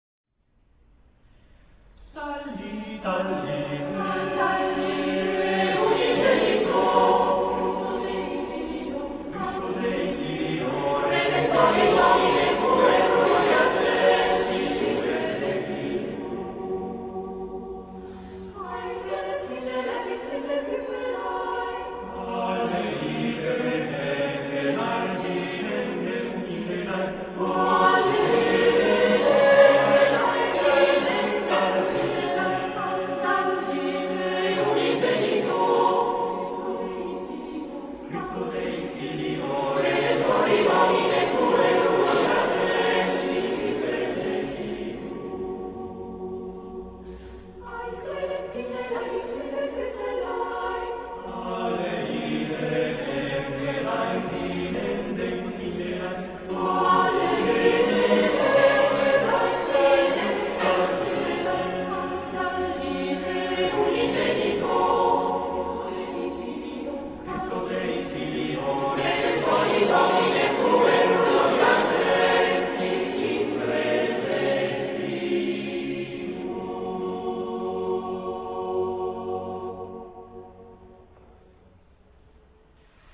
Qui potete ascoltare alcuni brani tratti dal repertorio del Coro Anthem.
I brani che seguono sono stati registrati dal vivo durante un concerto del 17 giugno 1997. La qualità della registrazione originale era molto buona, ma per motivi di spazio è stato necessario comprimere l'audio con una conseguente diminuzione della qualità.